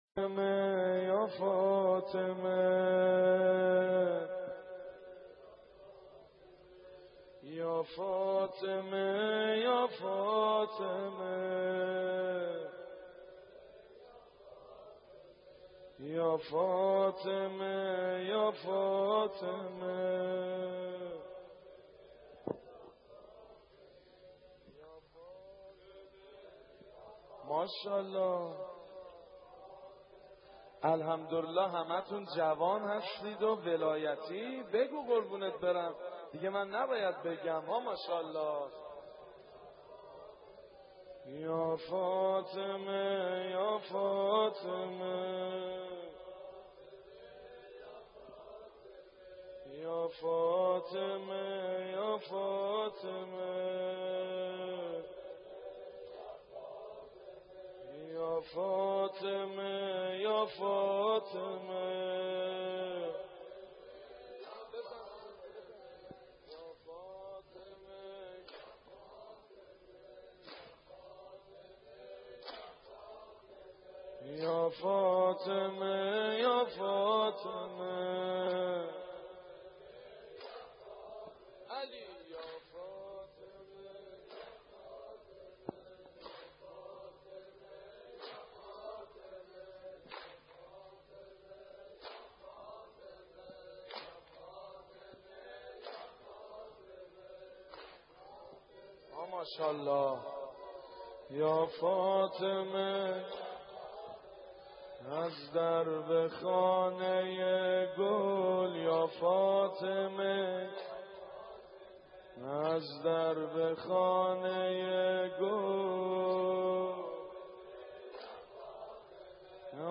مراسم سینه زنی در سوگ حضرت زهرا(س) با مداحی سید مجید بنی فاطمه (19:39)